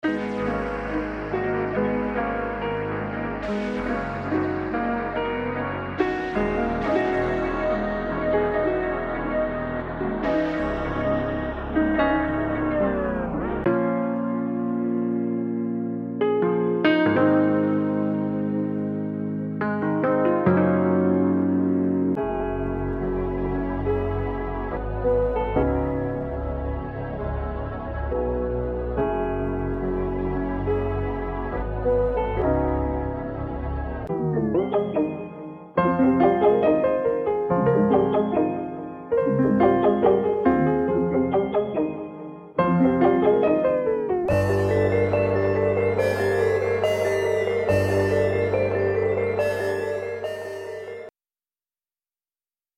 trap sample pack